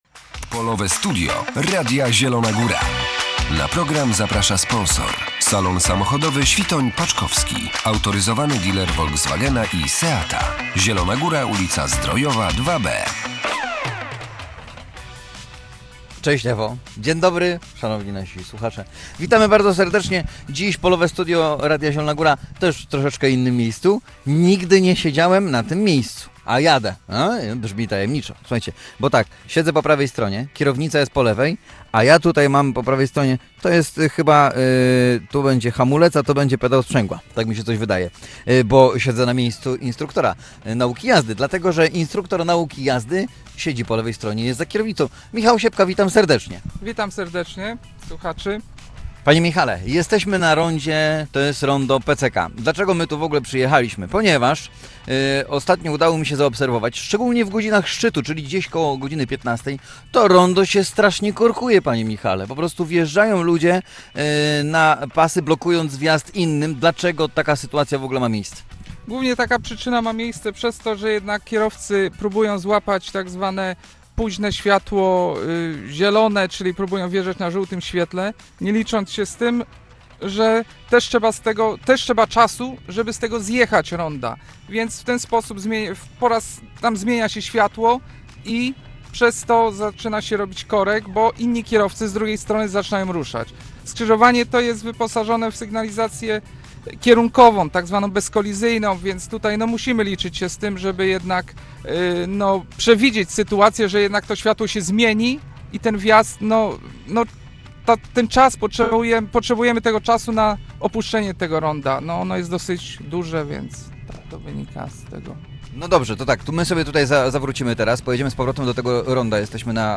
Polowe Studio Radia Zielona Góra